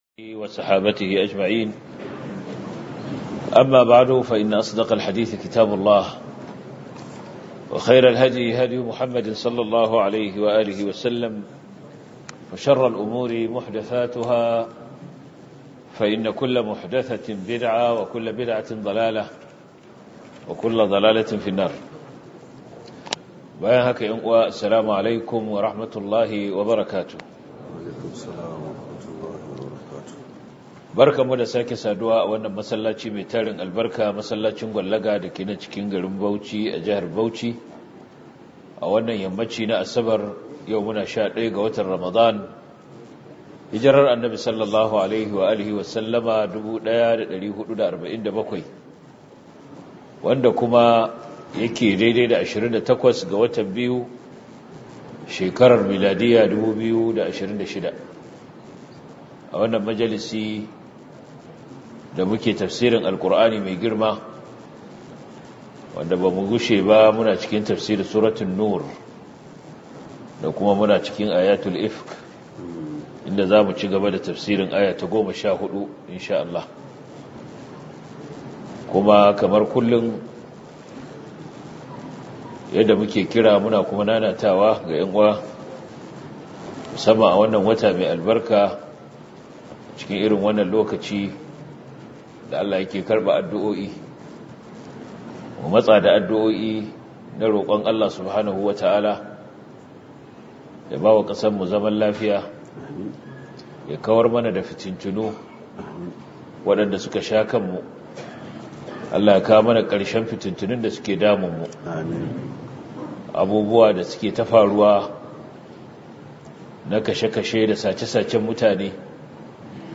← Back to Audio Lectures 11 Ramadan Tafsir Copied!